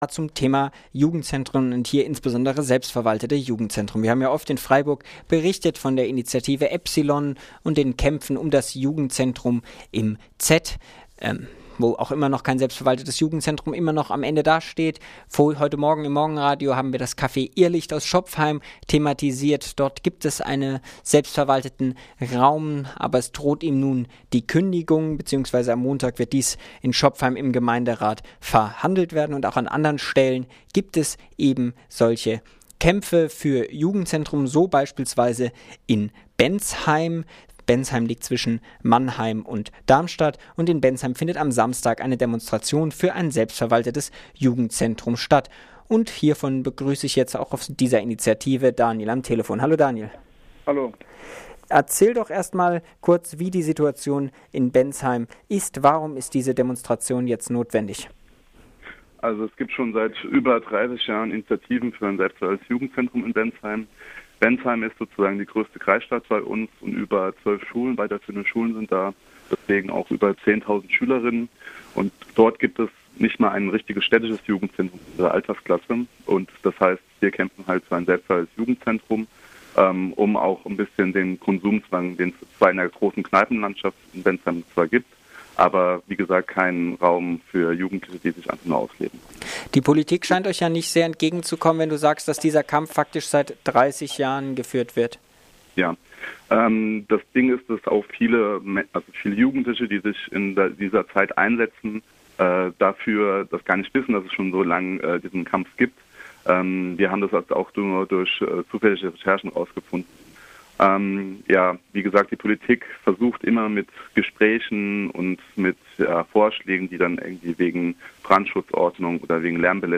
In Bensheim findet am 19.03.2011 eine Demo für ein selbstverwaltetes Jugendzentrum statt. Interview